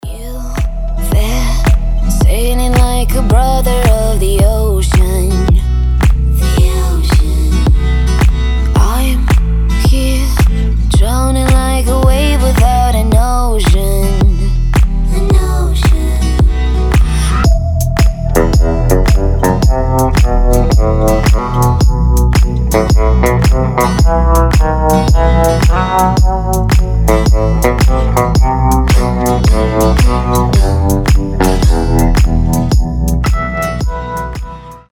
• Качество: 320, Stereo
гитара
ритмичные
Dance Pop
красивый женский голос